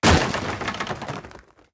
destroy6.ogg